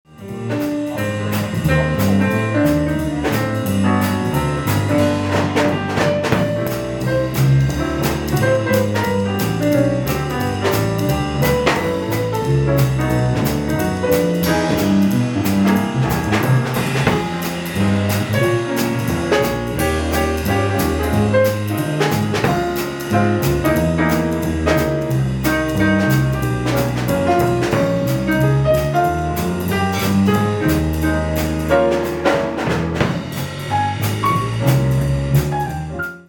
• Jazz